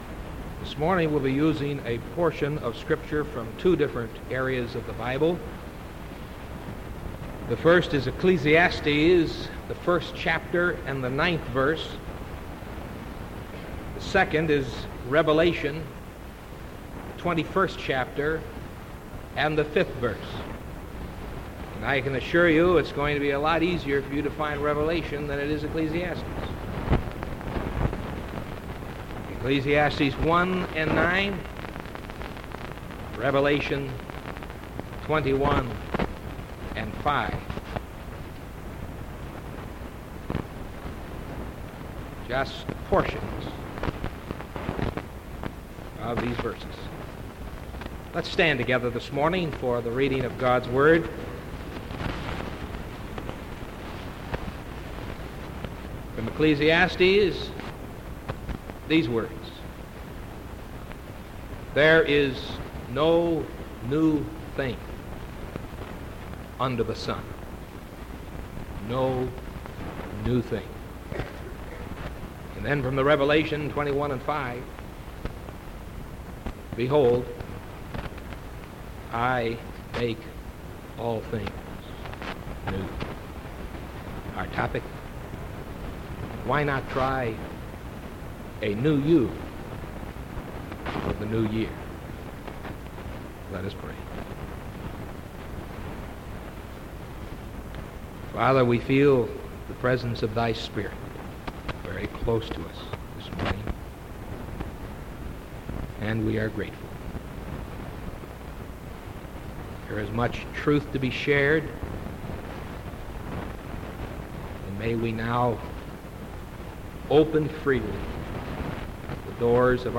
Sermon January 5th 1975 AM